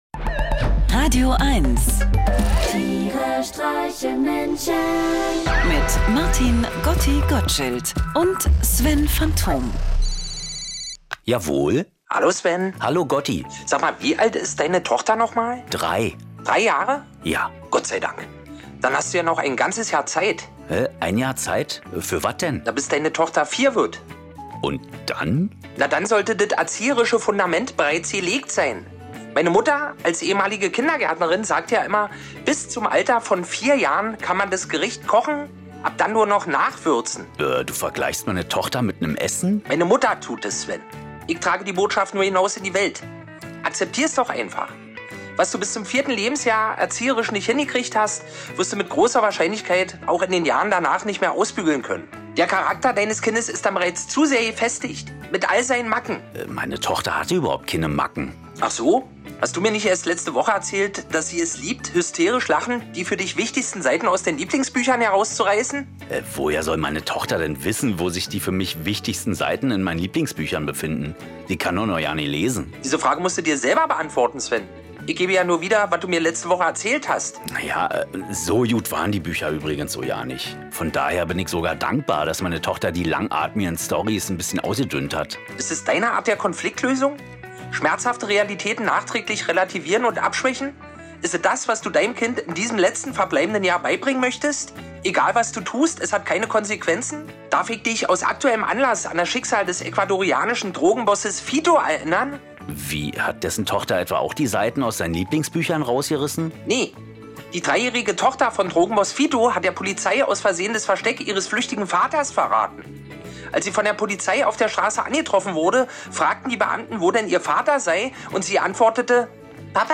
Einer liest, einer singt und dabei entstehen absurde, urkomische, aber auch melancholische Momente. Irgendwie mitten aus dem Leben und irgendwie bekloppt.